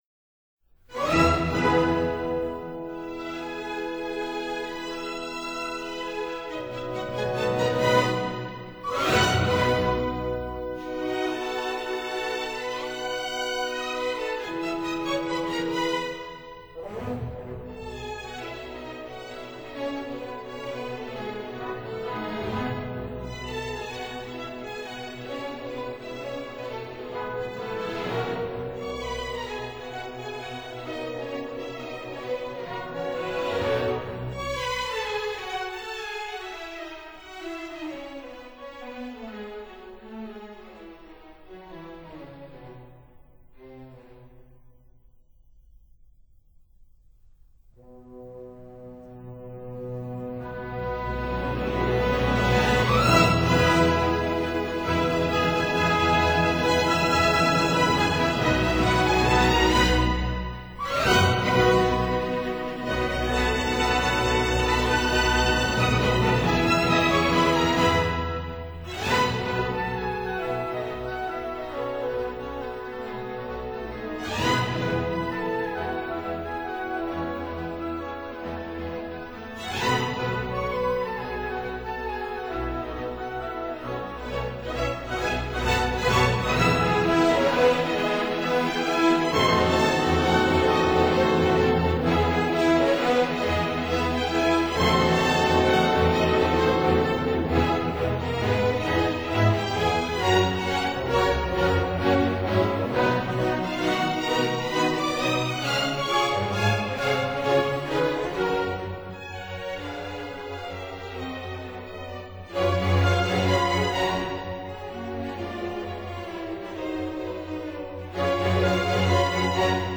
E Major, WWV35: I - Allegro Con Spirito [0:14:24.08] 02.